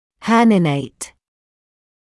[‘hɜːnɪeɪt][‘хёːниэйт]образовывать грыжу; выпячиваться; выходить за пределы полости